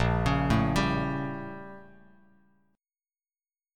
BbmM13 chord